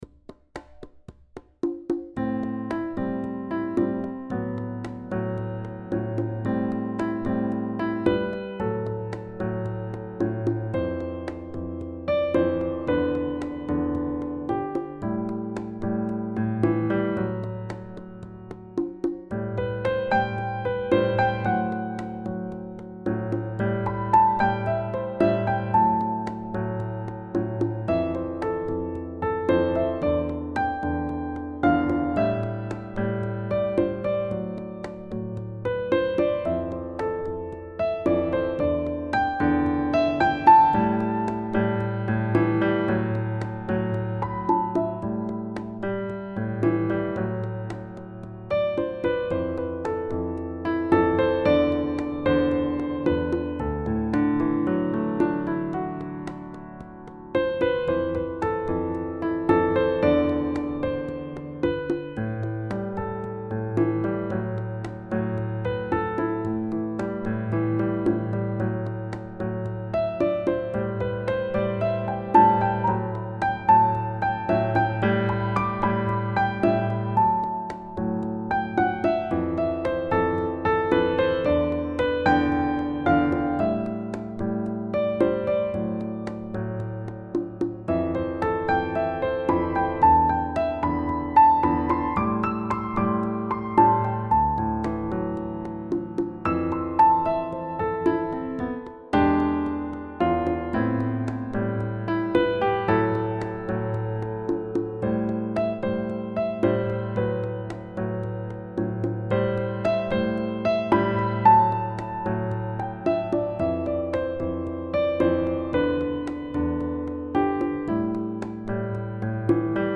Key: A minor with jazz harmonies
Time signature: 4/4, BPM ≈ 112
• Stylish Latin-jazz rhythms and accents
• Rich chord voicings and dynamic contrast